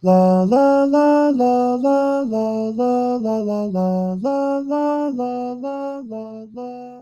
audio-to-audio pitch-correction
pitch correction on your voice
"scale": "Gb:min",